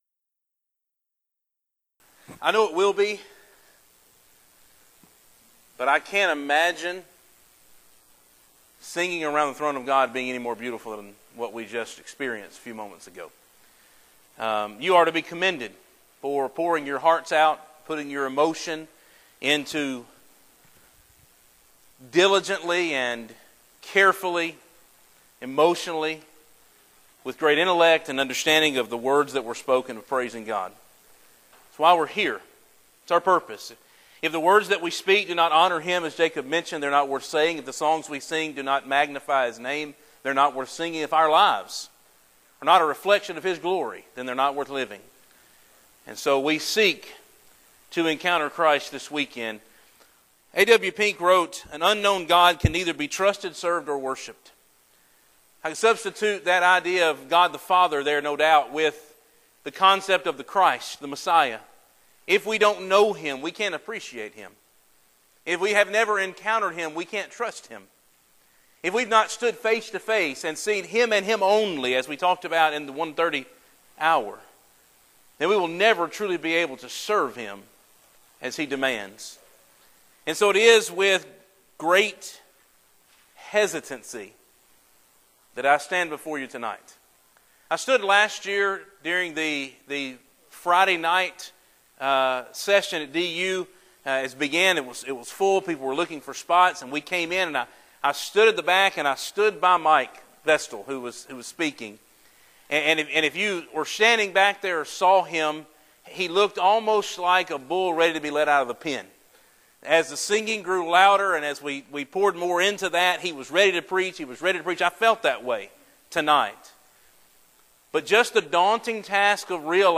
Event: Discipleship U 2016
Youth Sessions